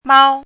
chinese-voice - 汉字语音库
mao1.mp3